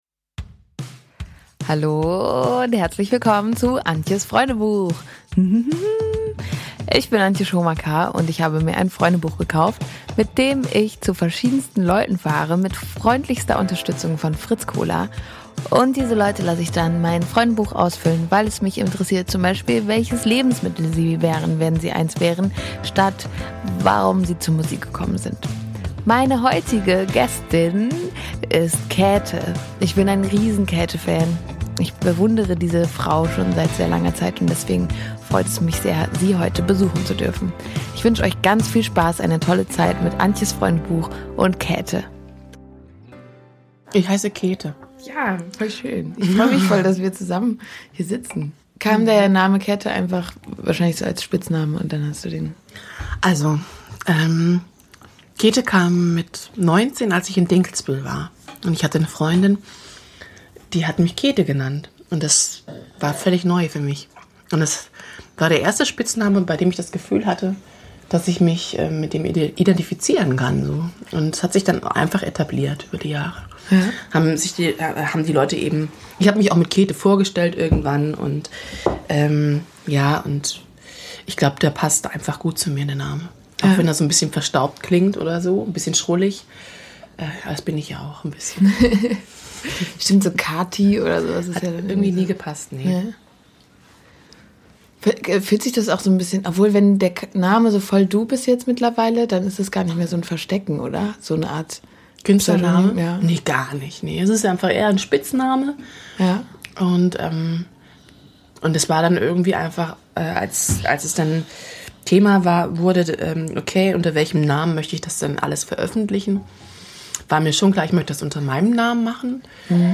Wir haben uns in einer Phase für denPodcast getroffen, in der wir uns beide mit dem Thema „Vergleichen“ viel beschäftigt haben und das istauch eines der Kernthemen in unserem Gespräch. Warum schaut man so viel zur Seite und wie gehtman mit äußeren Erwartungen um. Mir hat dieses Gespräch viel gebracht und ich hoffe euch gibt esvielleicht auch irgendwo einen neuen Blick oder Gedanken mit.